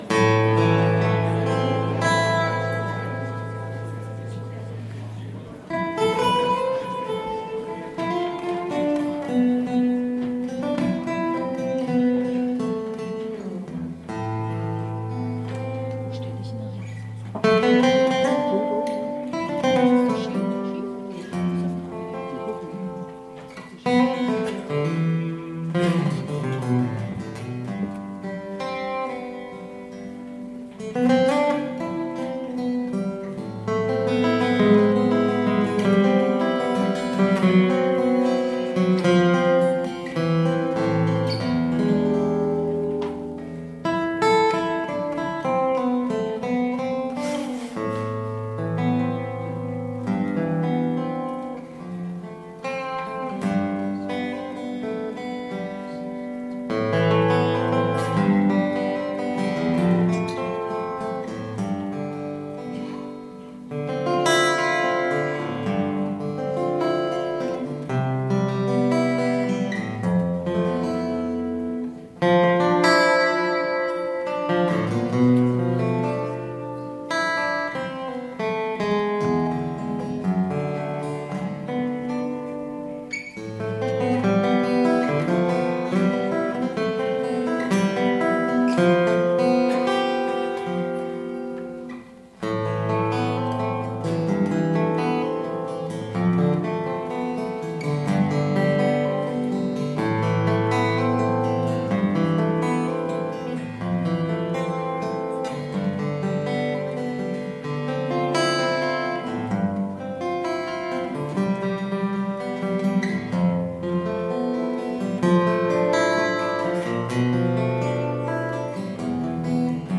Audiodokumentation Bundeskongress 2017: „Erinnern und Zeichen setzen! – Zeugnisse politischer Verfolgung und ihre Botschaft.“ 28./29./30. April 2017, Magdeburg, Maritim Hotel (Teil 1: 28. April)
Gitarrenimprovisation